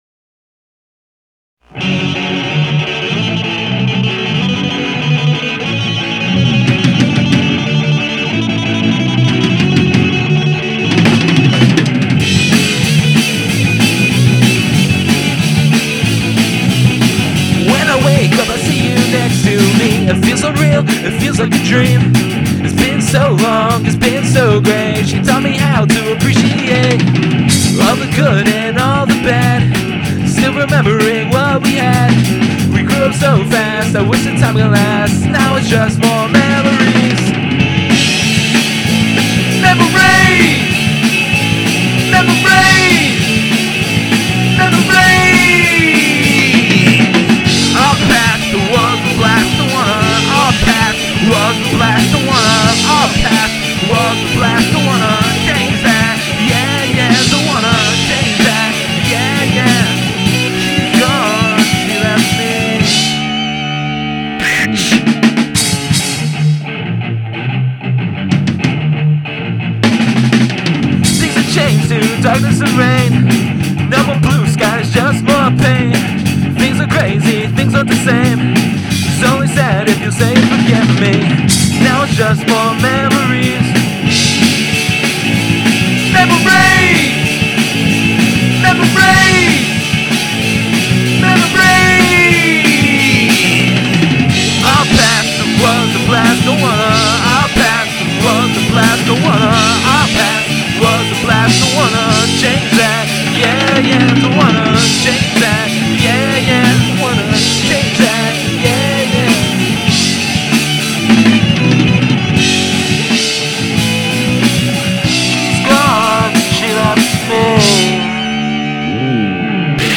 Quality: Good
An overdriven angsty punk song
drums, I played guitar and bass.